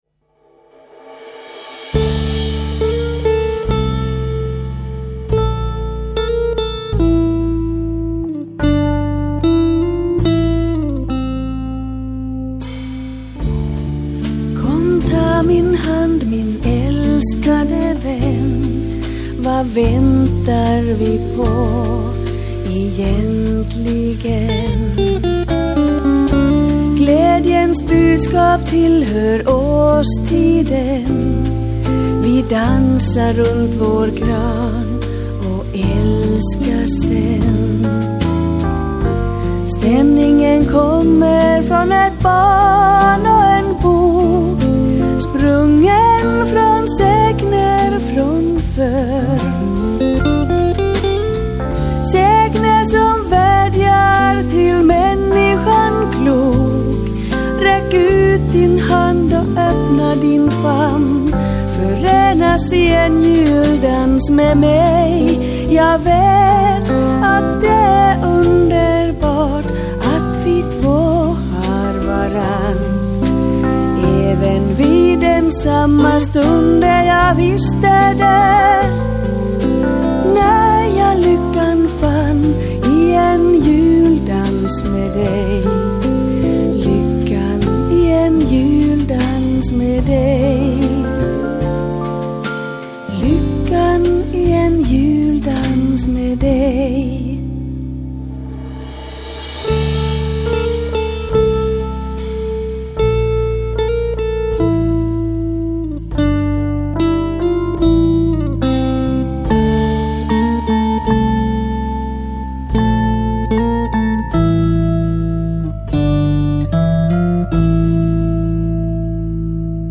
laulu
kitara
saksofoni
bassokitara
lyömäsoittimet